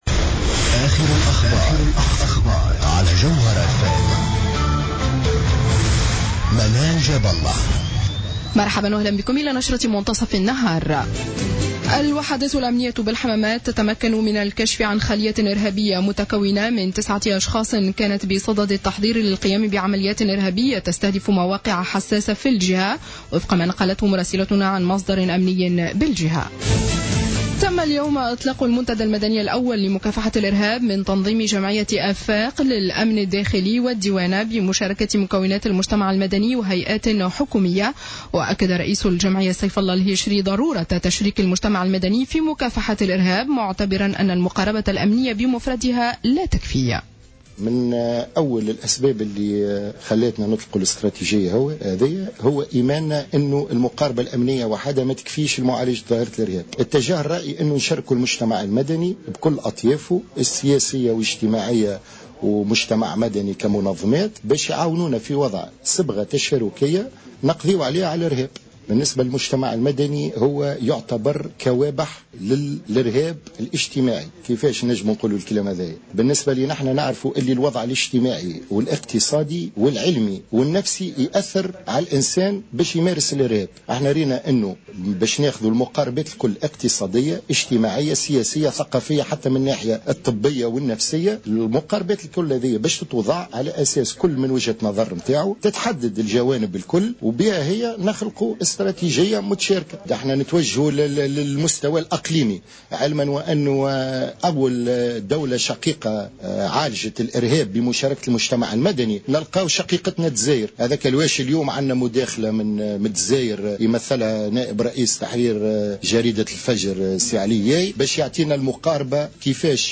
نشرة أخبار منتصف النهار ليوم 07-02-15